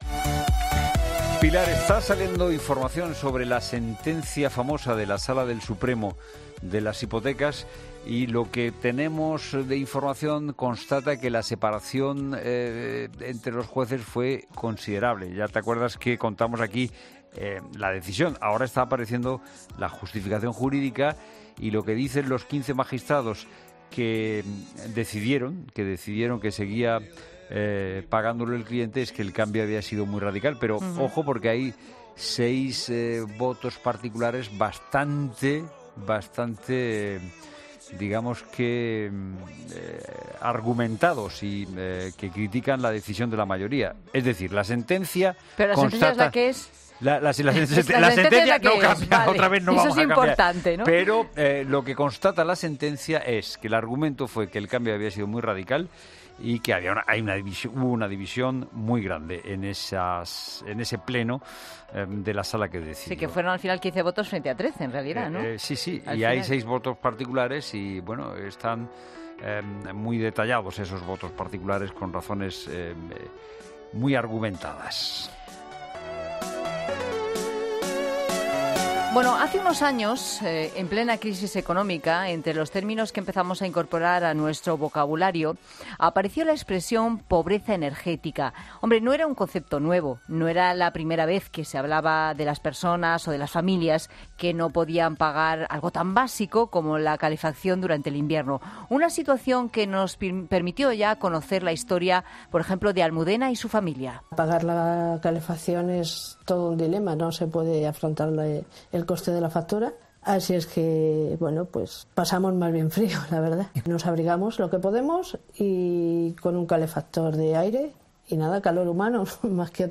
ESCUCHA LA ENTREVISTA COMPLETA EN 'LA TARDE' En Madrid, expertos nacionales e internacionales han analizado la pobreza energética en España; una situación que afecta a 13 millones de personas en nuestro país.